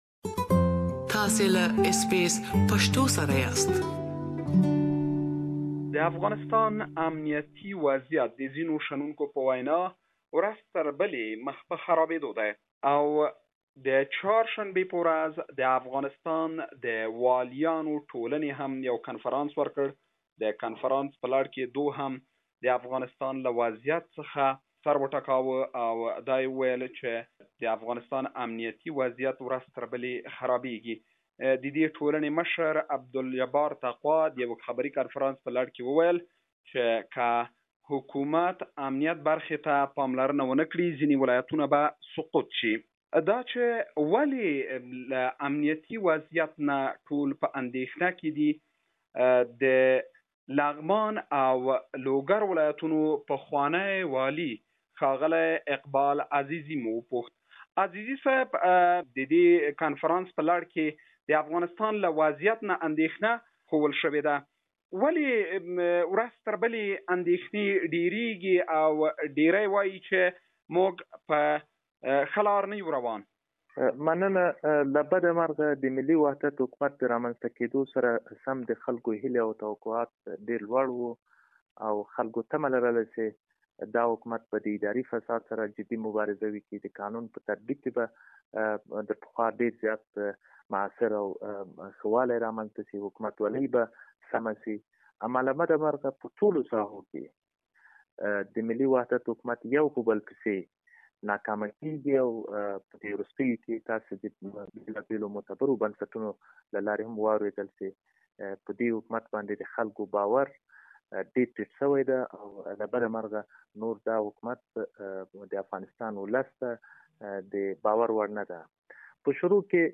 Afghans have different views on the security situation in Afghanistan; some believe that Afghan forces are capable of handling the security of Afghanistan while others think if the government doesn't take some immediate measures some of Afghan provinces will fall into Talibans hand. We interviewed former governor of Laghman and Logar provinces Mr Iqbal Azizi about this issue. Please listen to his full interview here.